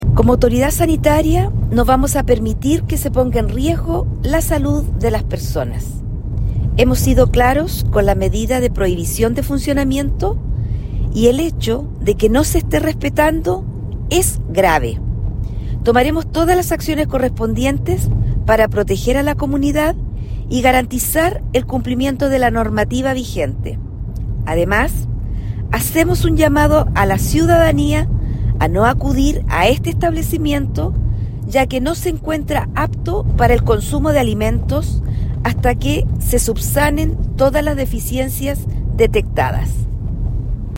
Frente a esta situación, la seremi de Salud de Atacama, Jéssica Rojas Gahona, fue enfática al señalar que se tomarán todas las medidas necesarias para proteger la salud de la comunidad: